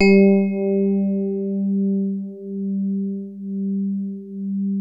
TINE HARD G2.wav